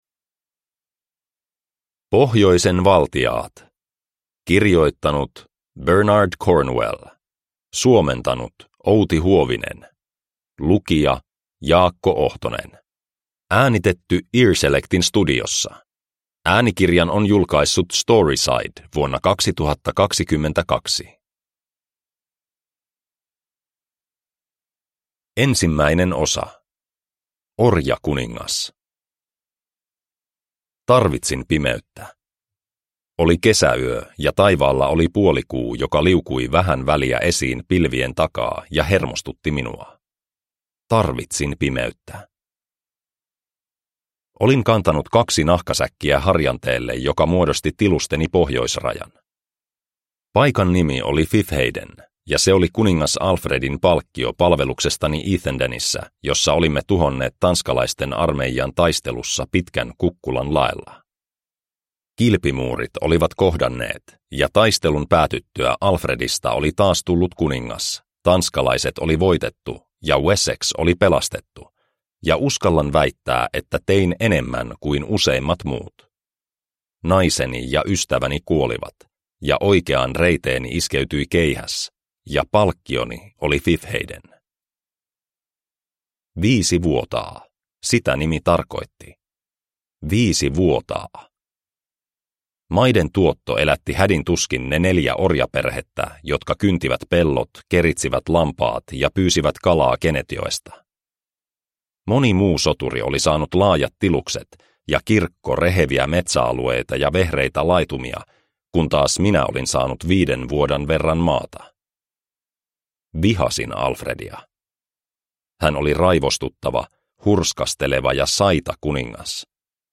Pohjoisen valtiaat – Ljudbok – Laddas ner